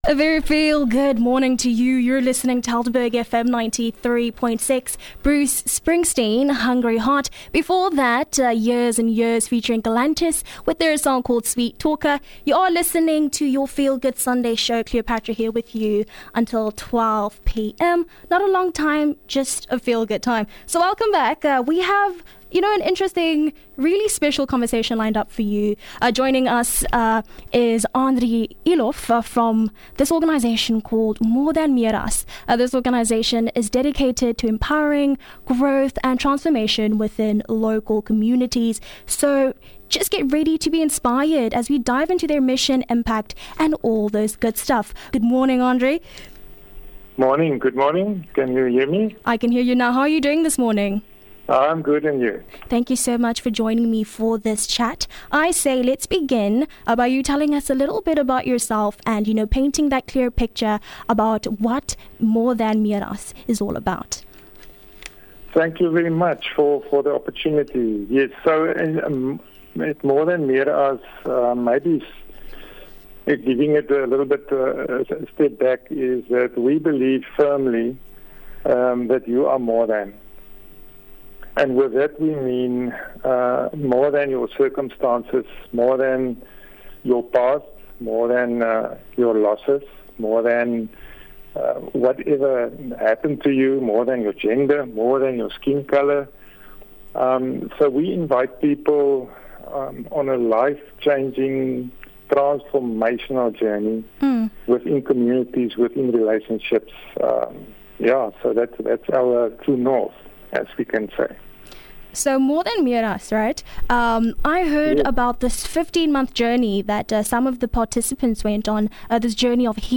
Interview on 2 June 2024